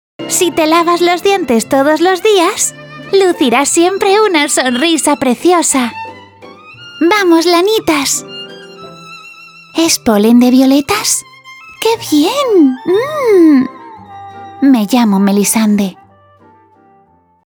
Locutora española 11 años experticia con home studio, disponibilidad, flexibilidad horarios y festivos y rapidez.
kastilisch
Sprechprobe: Sonstiges (Muttersprache):
Voice over freelance with home studio pro.